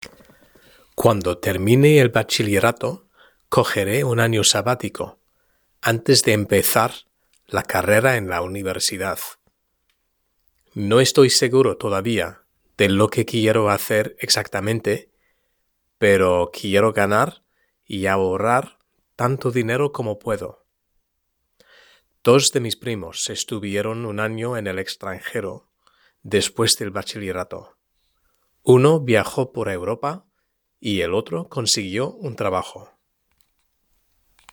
Lectura en voz alta: 1.3 La educación y el trabajo #2 (H)